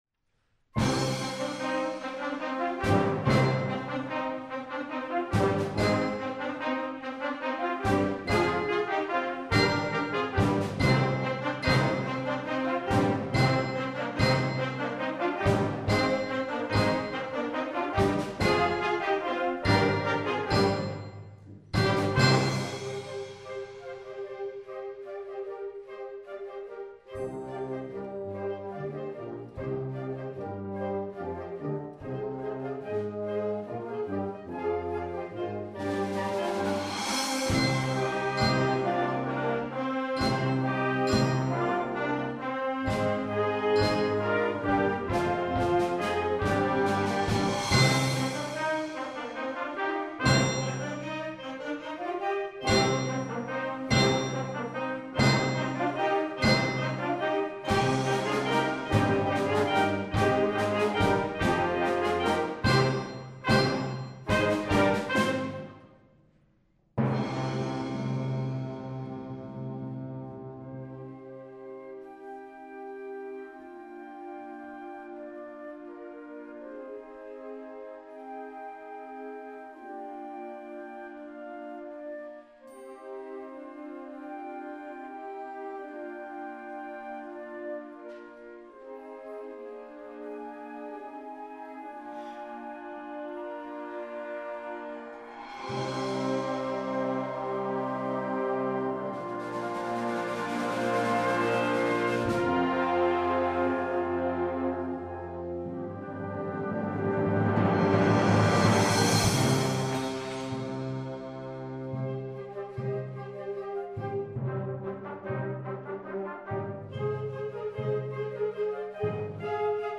Schulorchester
Konzertwertung 2015, Riedau, Pramtalsaal